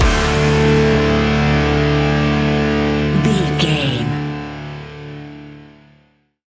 Ionian/Major
D♭
hard rock
instrumentals